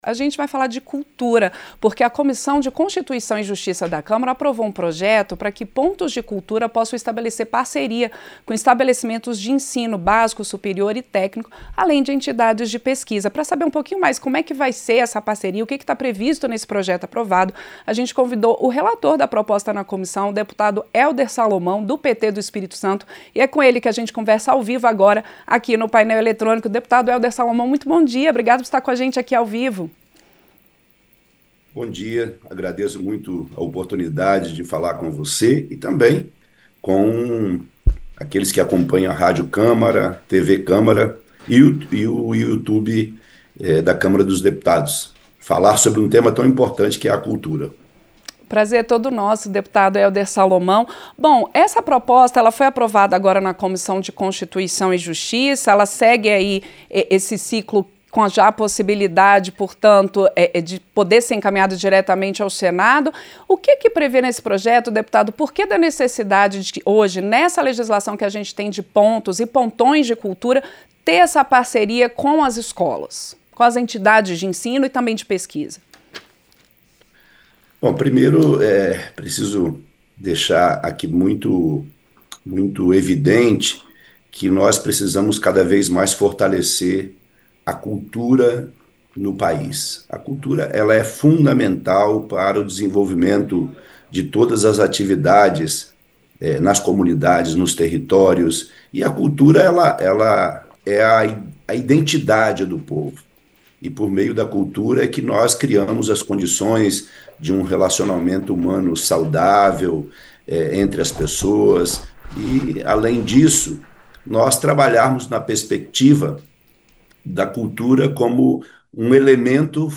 Entrevista - Dep. Helder Salomão (PT-ES)